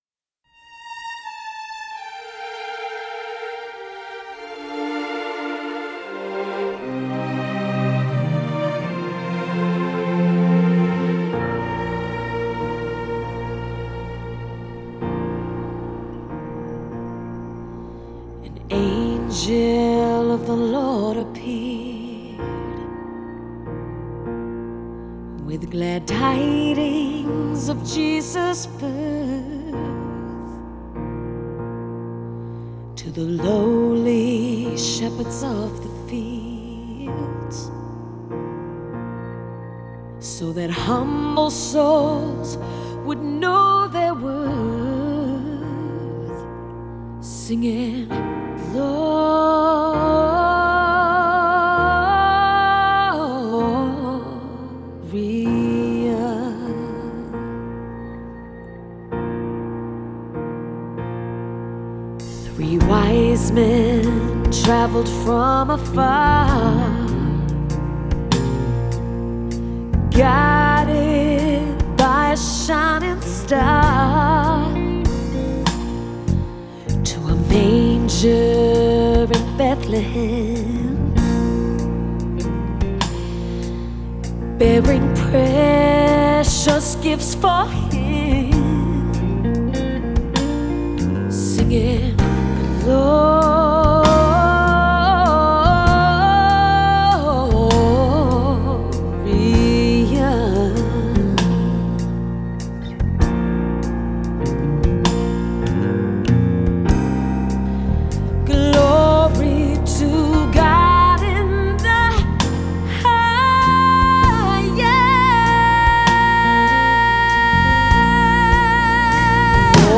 The album is warm, bold and festive.